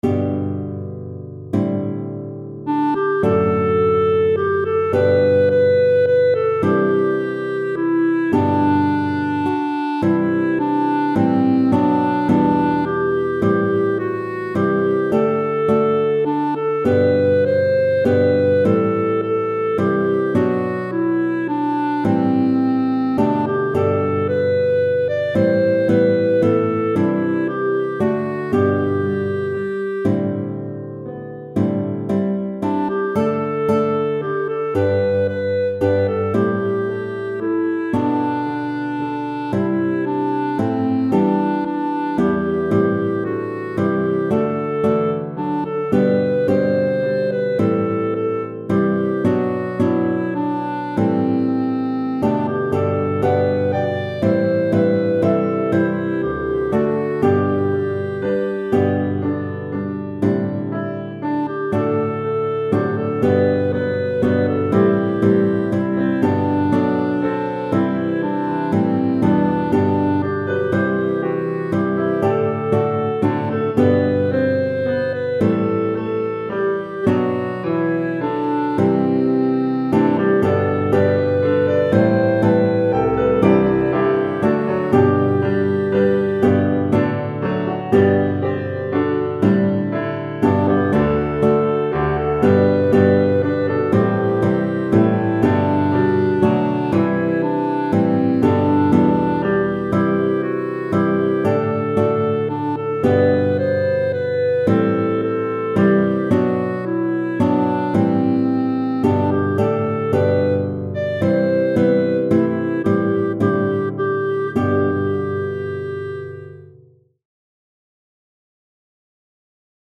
Song with clarinet for melody:
This is mainly a solo for treble voice, although some extra voices could be added. The accompaniment is written for guitar and optional piano.